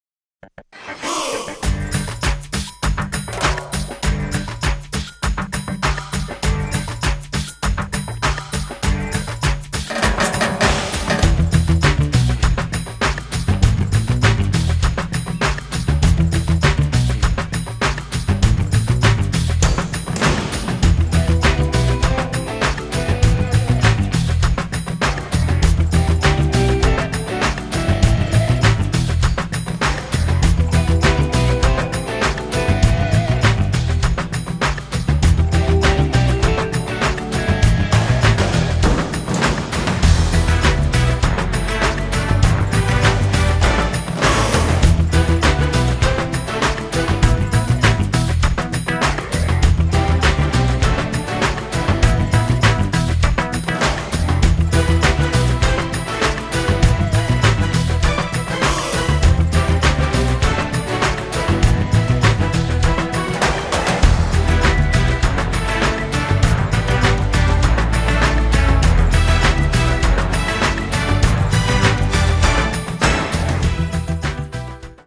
SCORE PRESENTATION